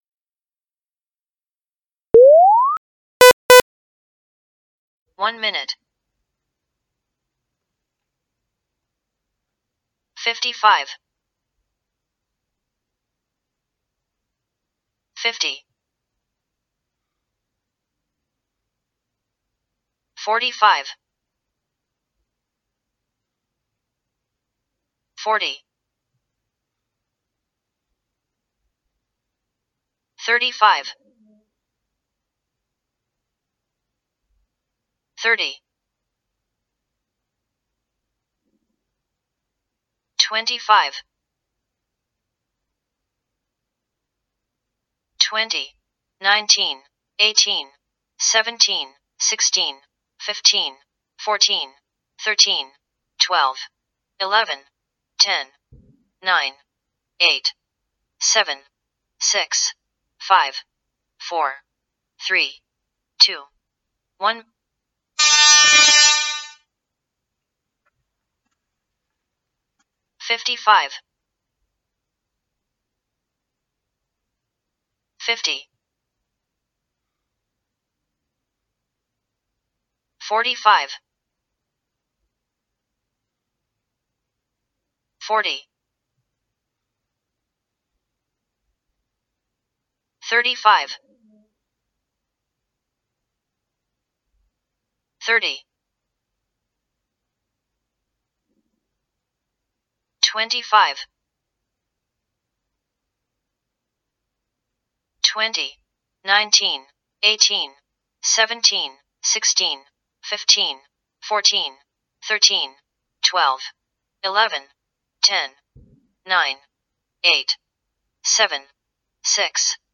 Start - countdown
Countdown1min(EN)+extra start 1min.mp3
countdown_EN_1min_plus_extra_start_1min.mp3